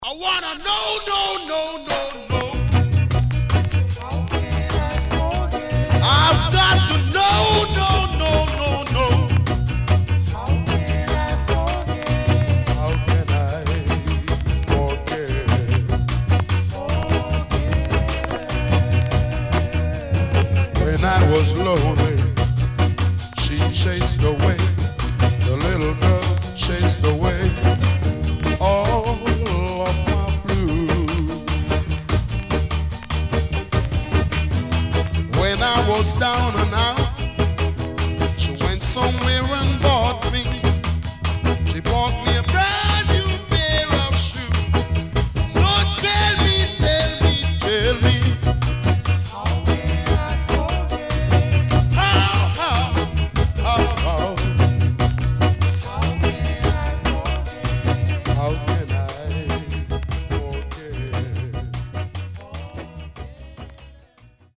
SIDE A:出だしに傷がありノイズ入ります。少しプチノイズあり。